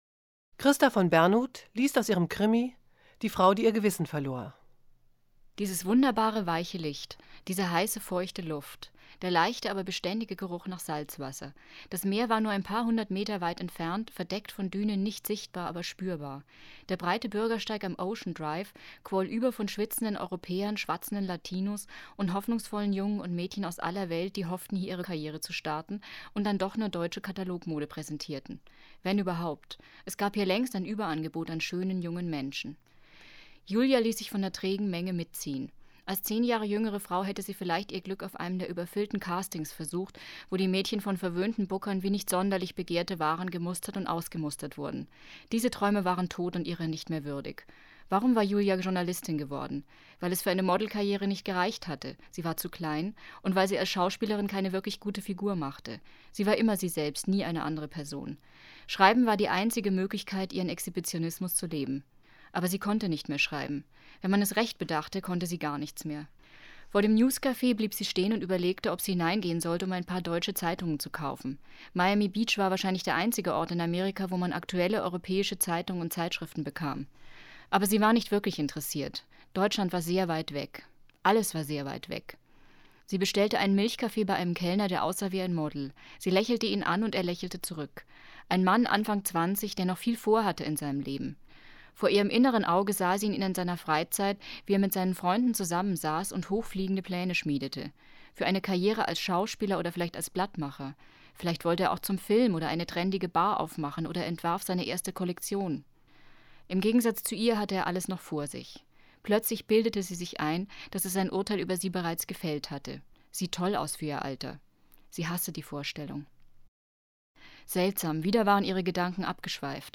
Es umfasst 40 CDs, auf denen insgesamt 573 Lesungen enthalten sind.